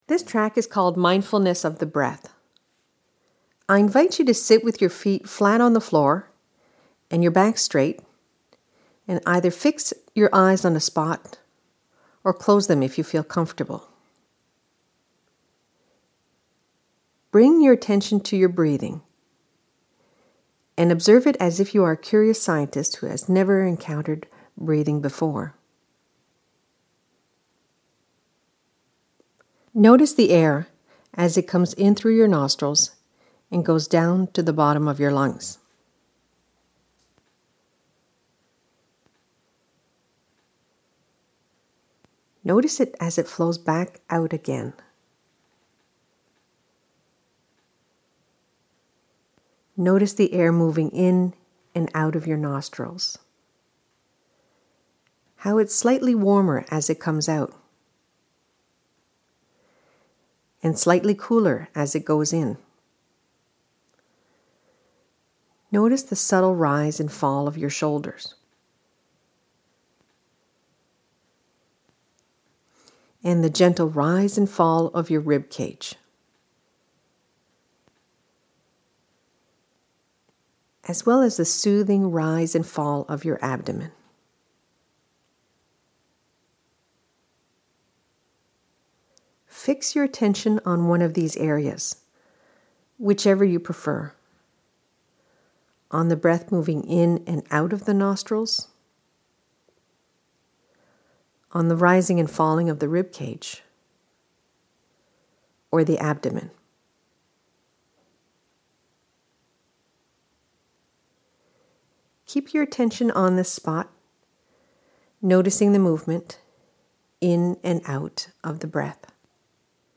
This is one of several audio exercises based on the concepts of Acceptance and Commitment Therapy ("ACT").